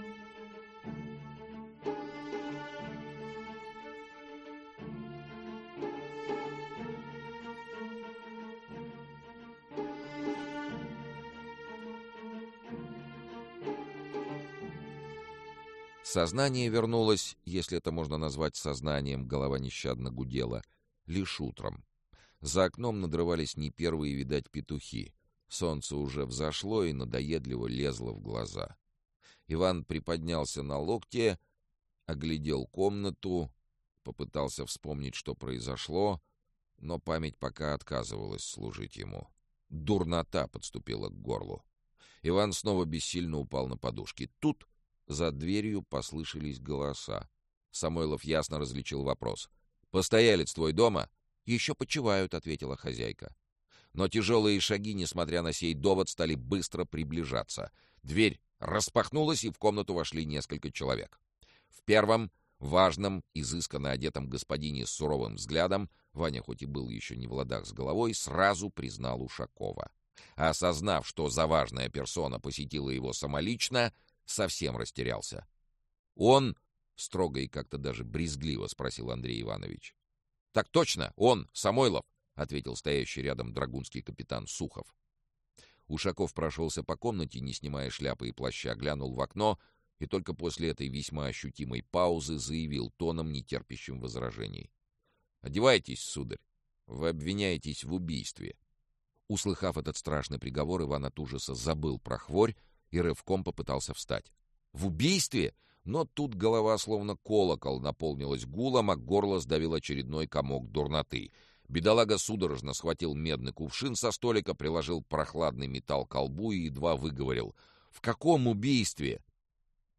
Аудиокнига Записки экспедитора Тайной канцелярии. Особое положение | Библиотека аудиокниг
Особое положение Автор Олег Рясков Читает аудиокнигу Сергей Чонишвили.